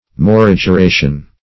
Search Result for " morigeration" : The Collaborative International Dictionary of English v.0.48: Morigeration \Mo*rig`er*a"tion\, n. [L. morigeratio.] Obsequiousness; obedience.